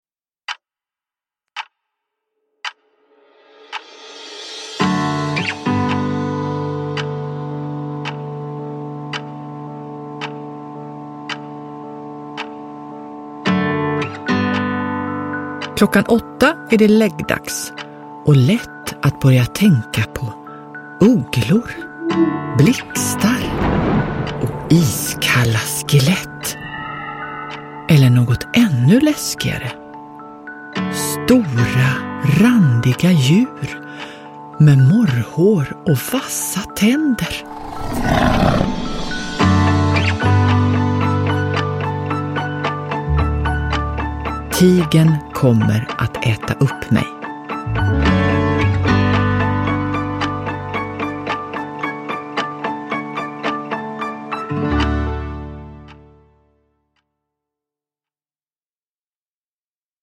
Tigern kommer att äta upp mig! – Ljudbok – Laddas ner